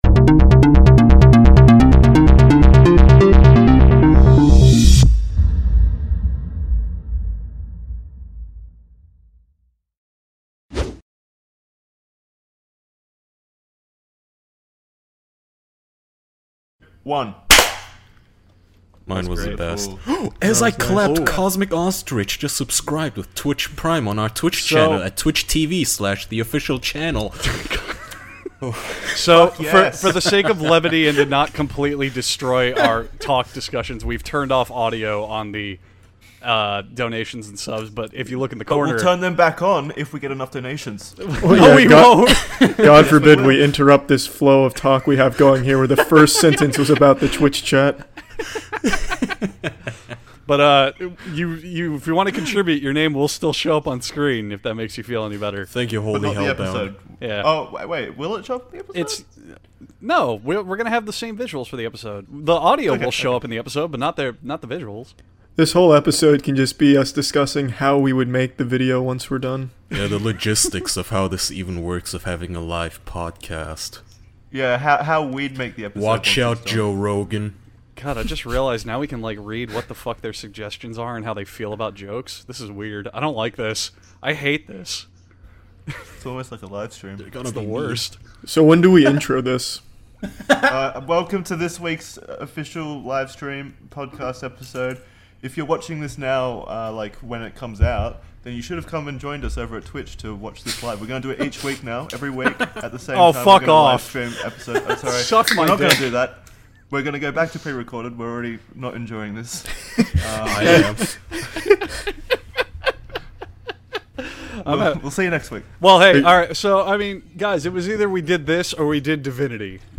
Four close man friends gather around to live-stream their show to the internet for the first time.